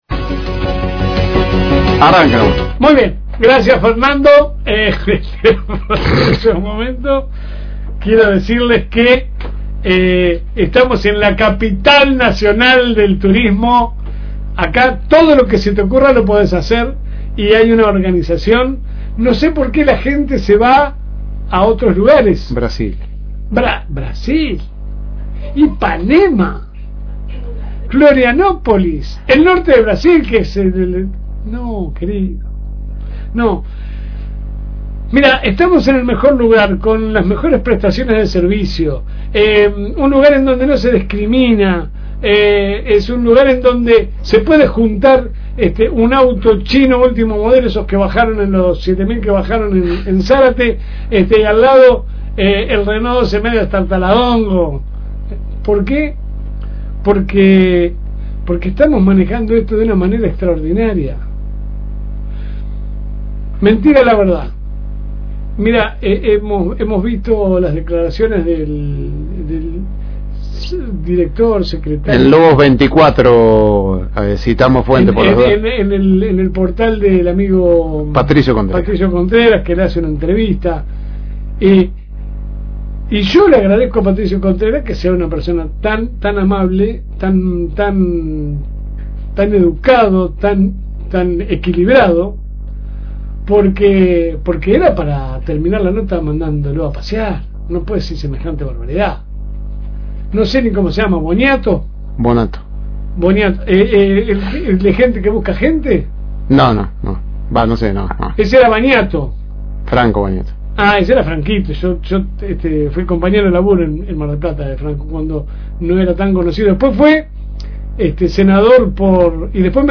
La editorial a continuación